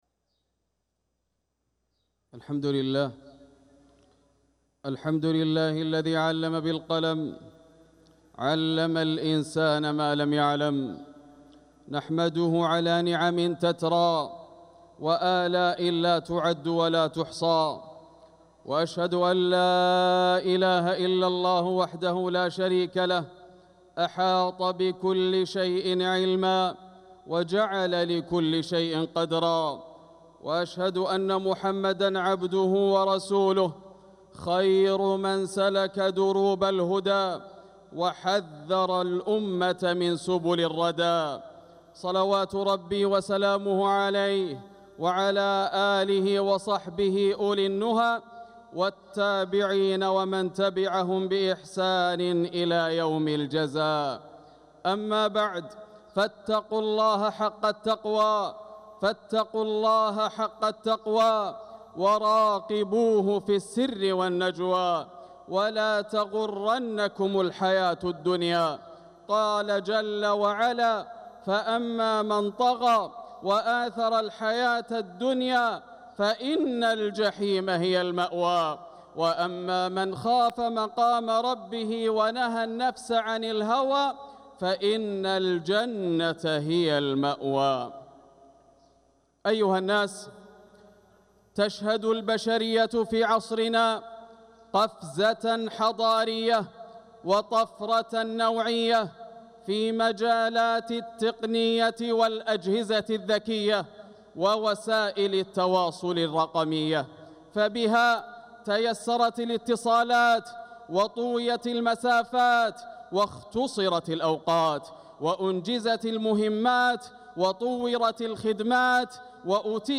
خطبة الجمعة 7 صفر 1447هـ بعنوان وسائل التواصل بين النعمة والنقمة > خطب الشيخ ياسر الدوسري من الحرم المكي > المزيد - تلاوات ياسر الدوسري